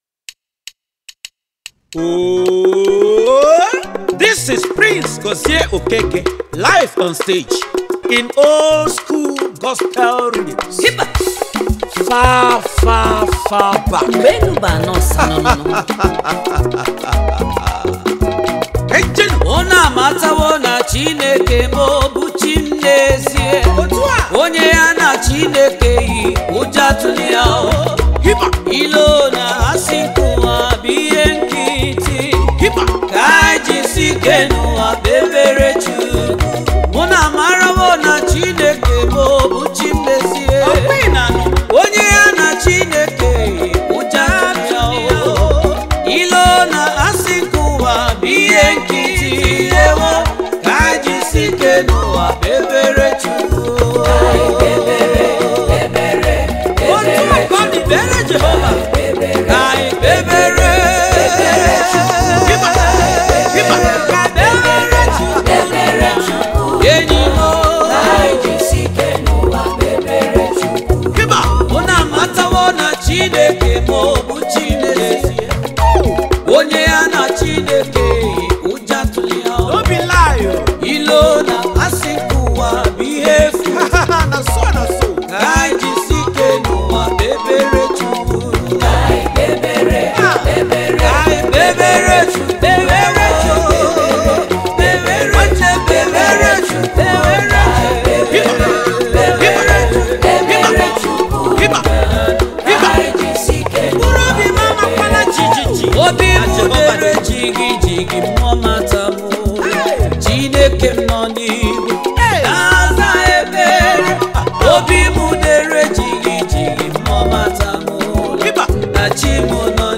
January 21, 2025 Publisher 01 Gospel 0